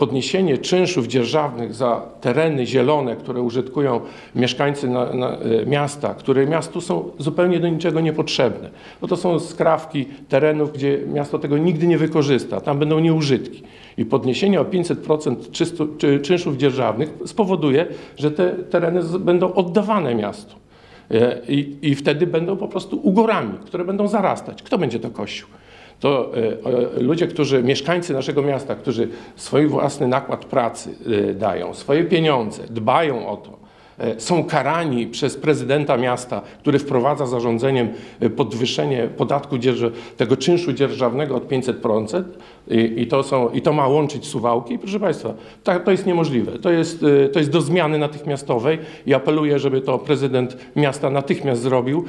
Lepszą ochronę środowiska obiecywał w czwartek (27.09.18) na konferencji prasowej